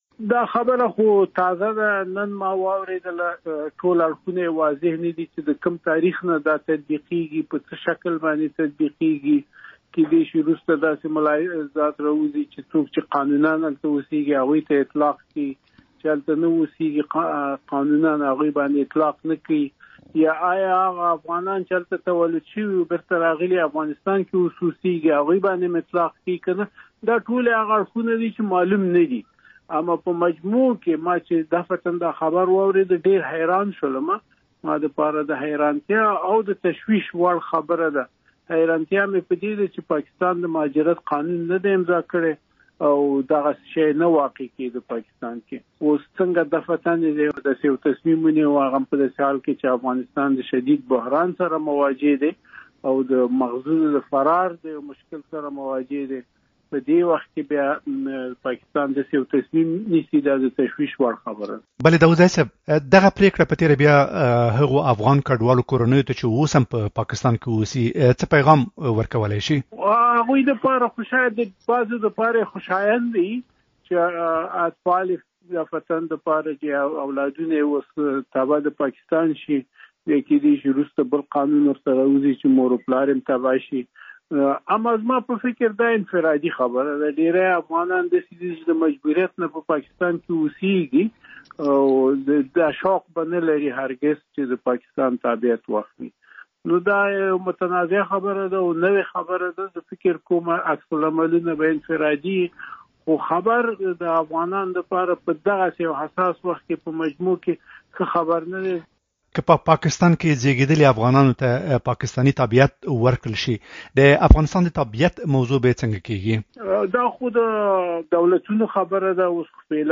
مرکه
په پاکستان کې د افغانستان پخوانی سفیر محمد عمر داوودزی